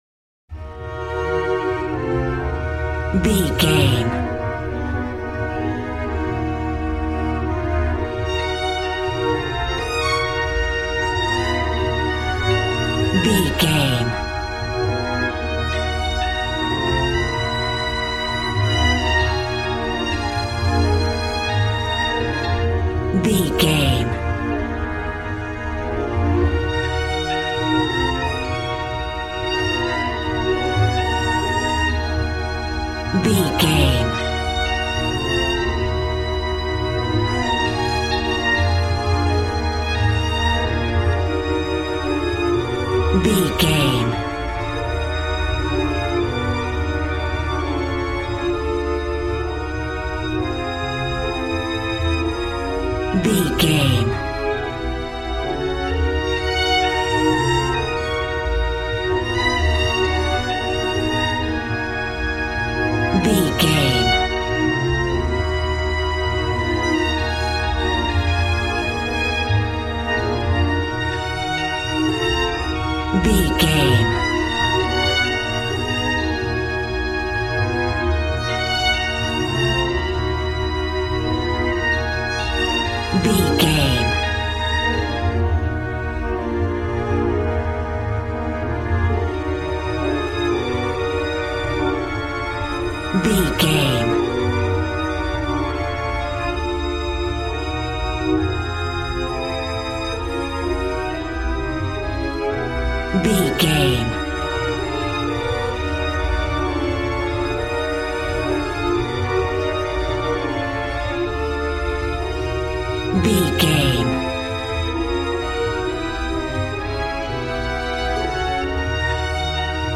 Aeolian/Minor
dramatic
epic
percussion
violin
cello